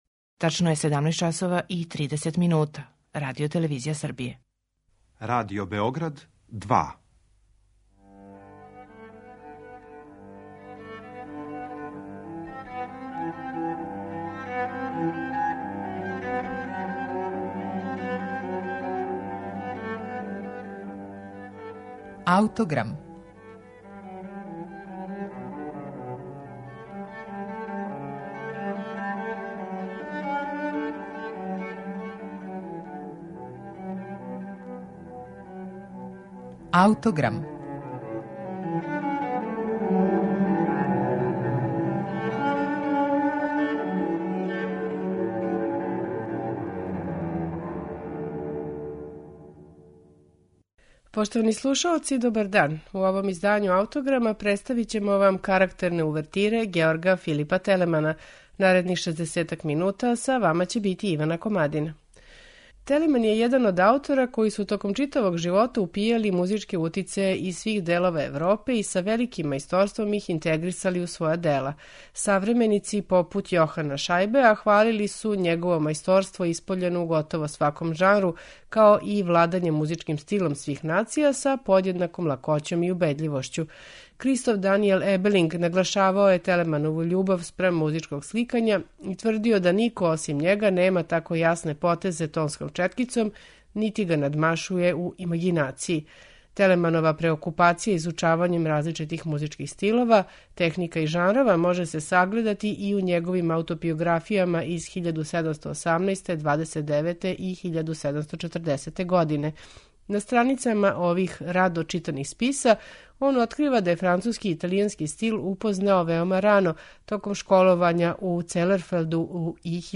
Телеманов космополитски музички стил у данашњем Аутограму представићемо уз две карактерне увертире